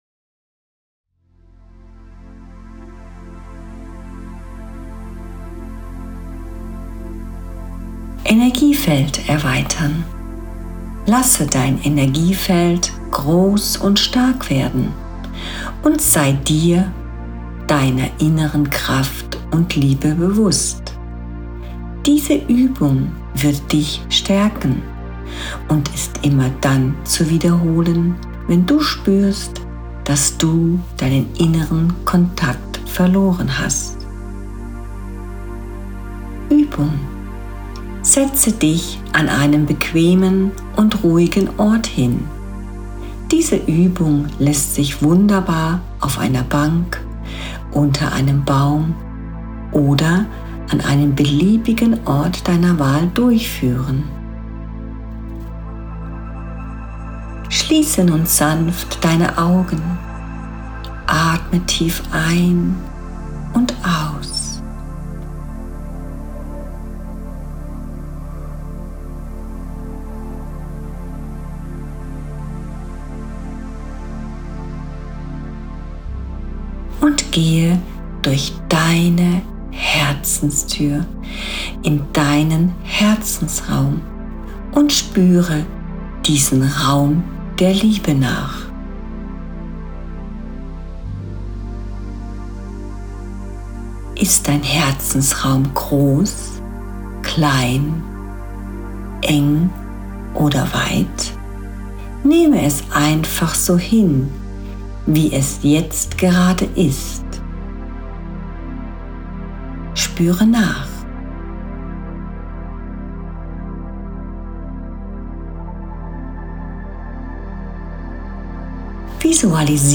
Meditation/Übung, Energiefeld erweitern